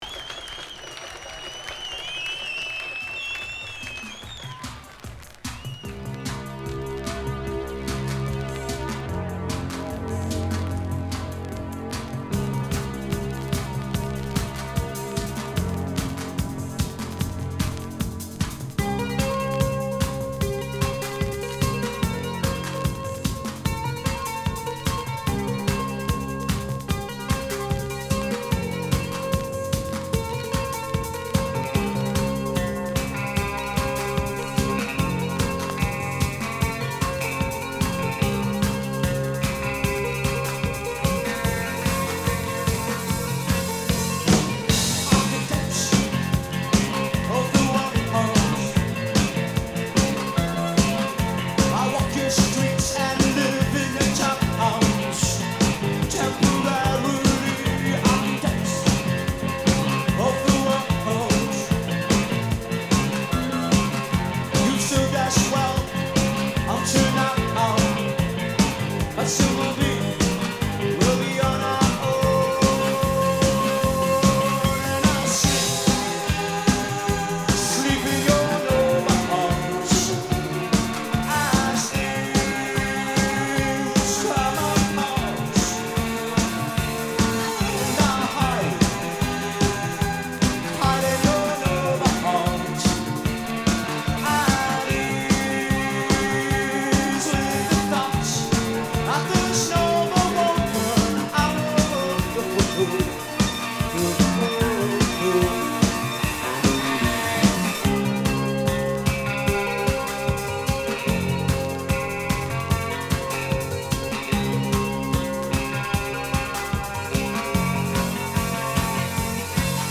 Recorded at Barrymores, Ottawa, October 26, 1982